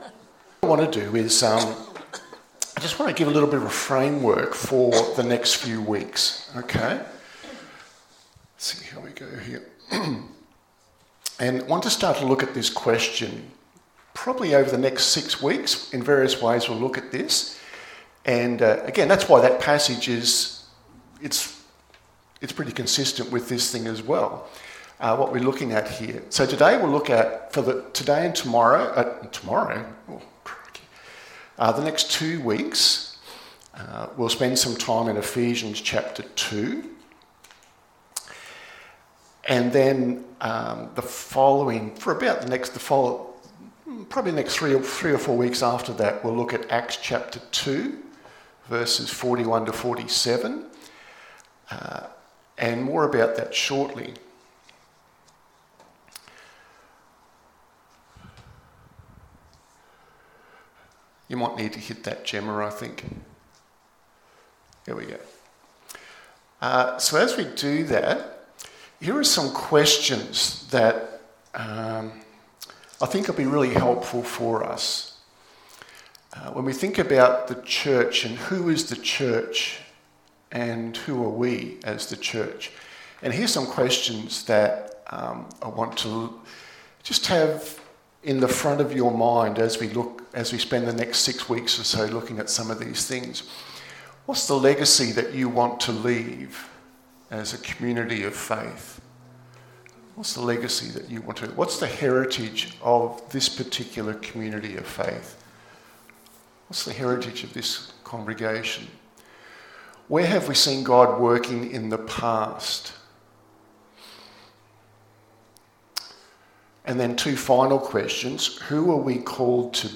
Sermon audio, The Gospel changes everything.mp3